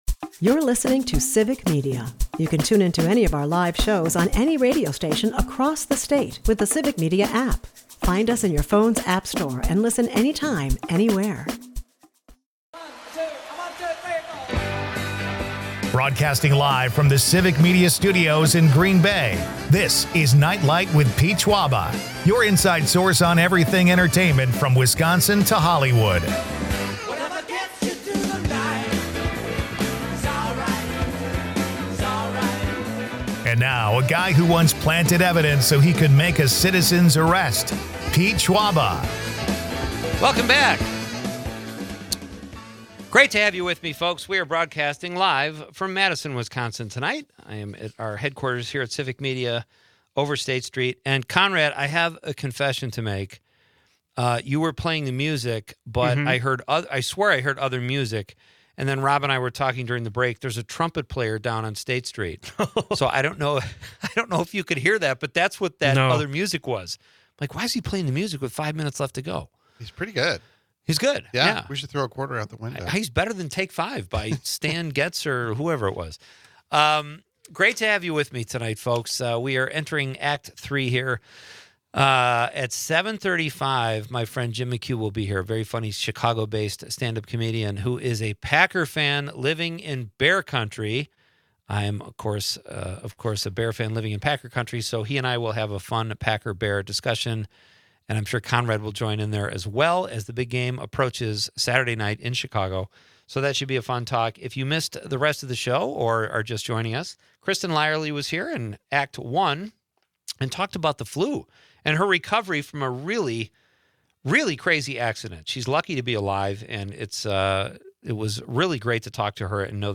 Listener texts reveal a love for cheesy soap operas, with favorites like 'Days of Our Lives' and 'Dallas'. A lively mix of sports, laughs, and local flavor!